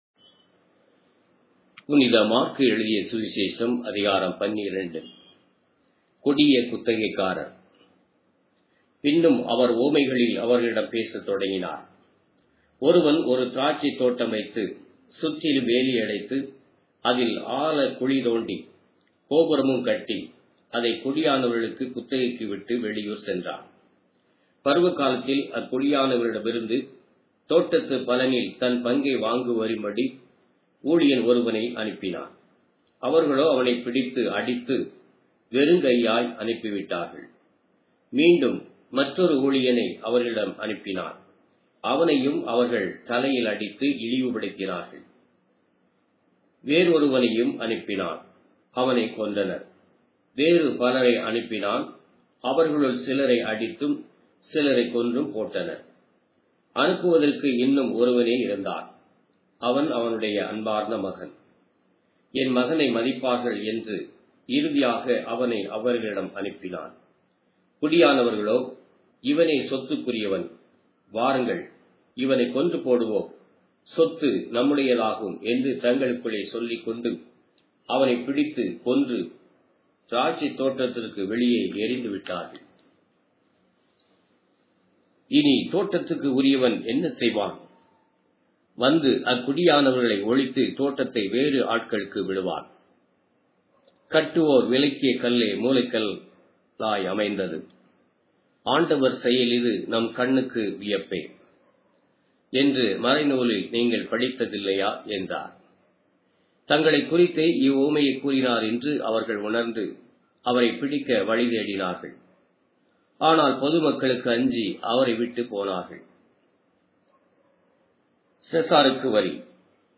Tamil Audio Bible - Mark 15 in Rcta bible version